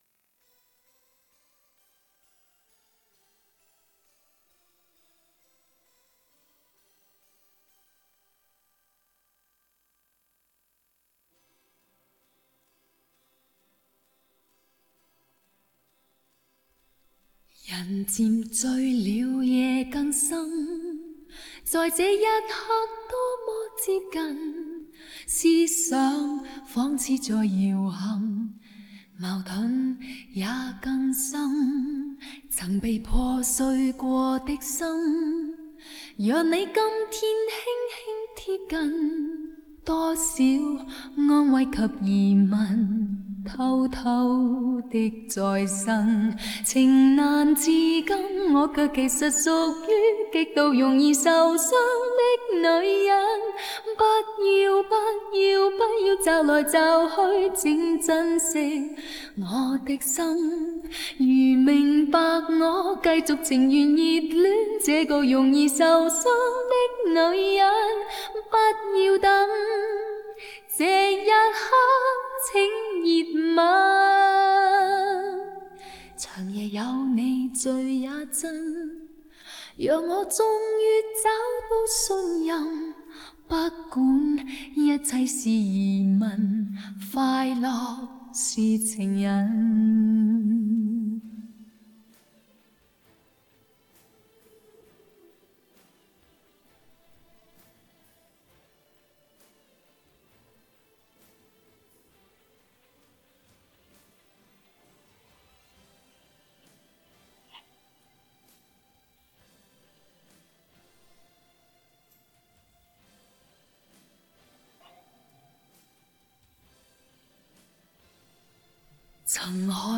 Rename 8_8_(Vocals).wav to 8.wav